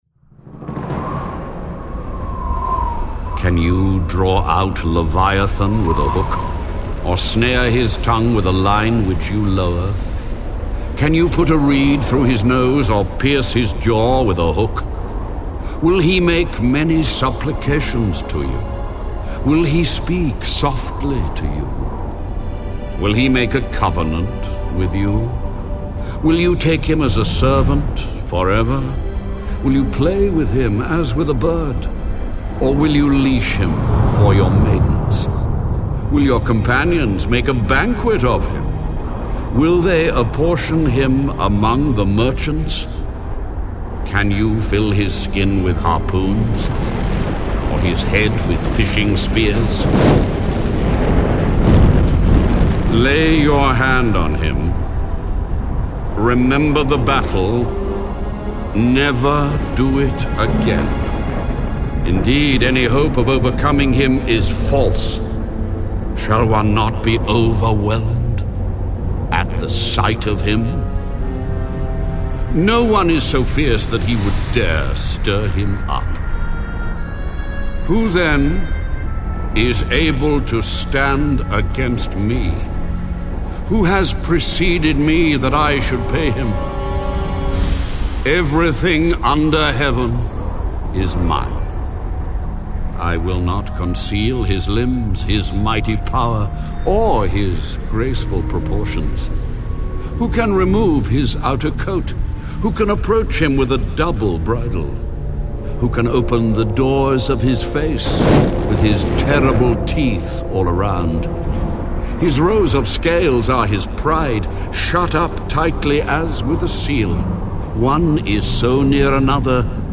Bible reading on Job 41:25
(Audio Bible)